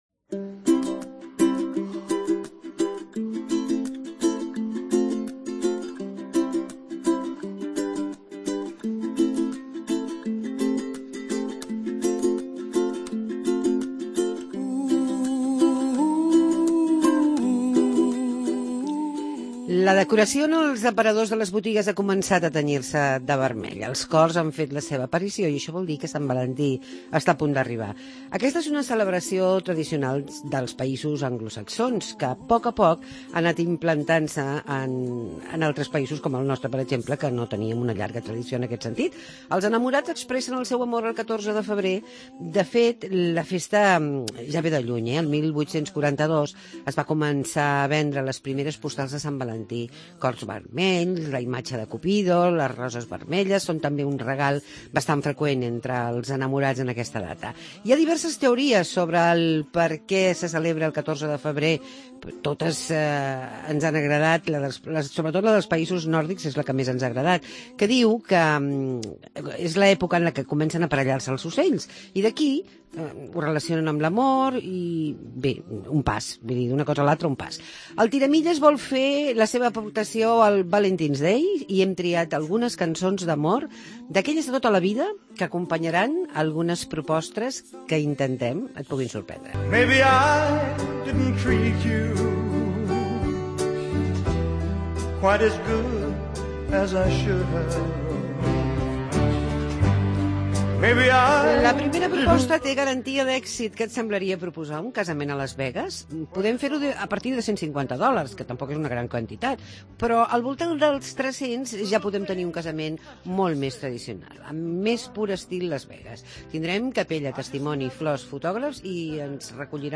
Arriba Sant Valentí, i al Tiramilles hem fet un reportatge sobre aquest dia